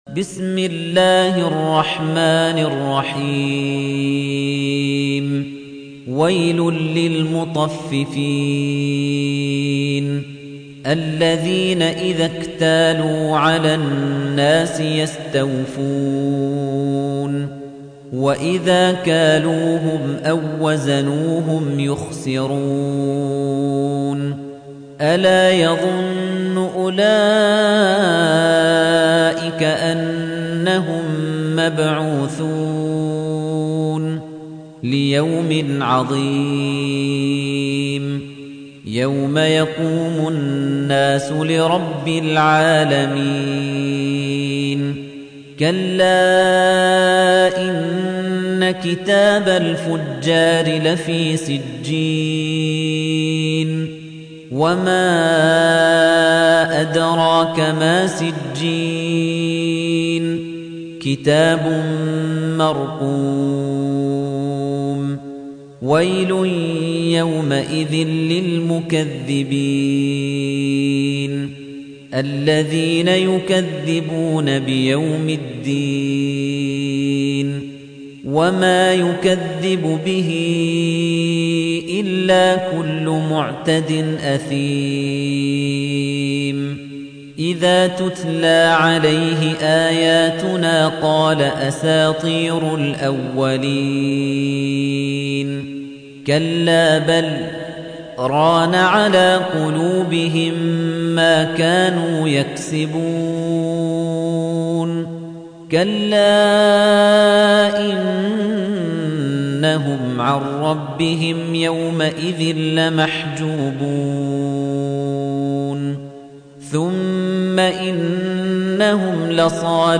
تحميل : 83. سورة المطففين / القارئ خليفة الطنيجي / القرآن الكريم / موقع يا حسين